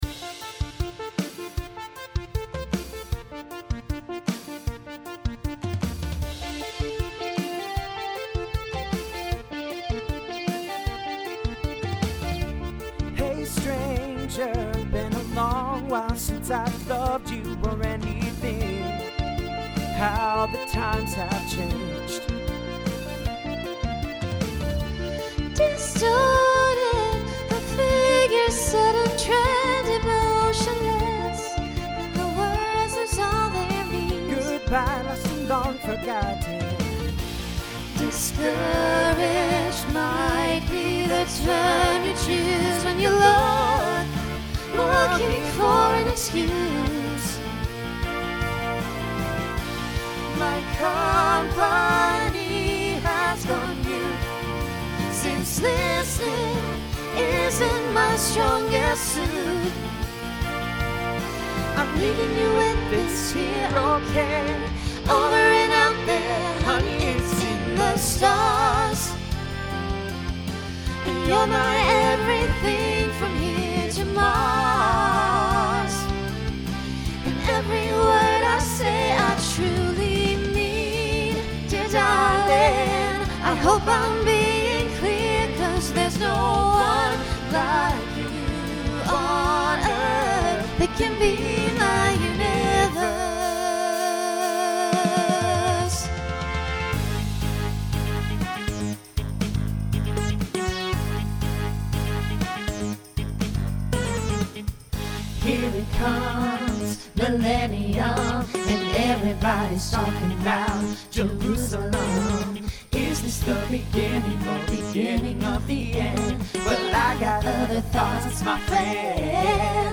Genre Pop/Dance , Rock
Voicing Mixed